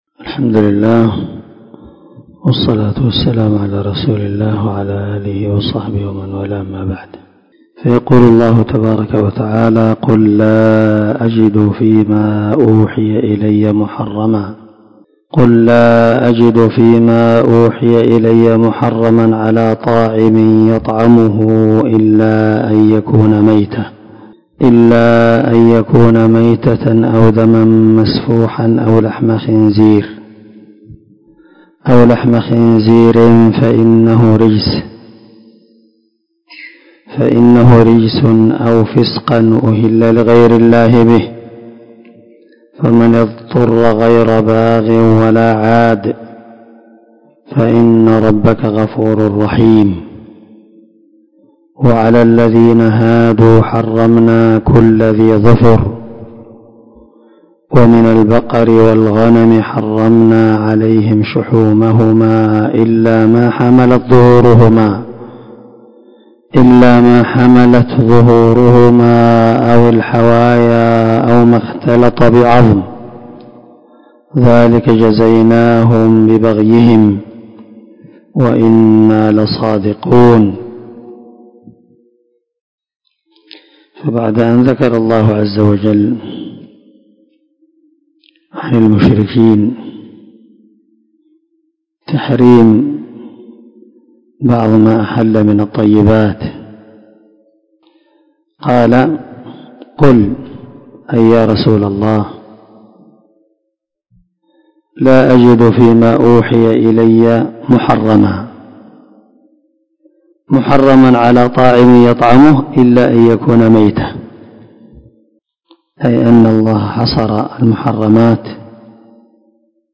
441الدرس 49 تفسير آية ( 145 – 146 ) من سورة الأنعام من تفسير القران الكريم مع قراءة لتفسير السعدي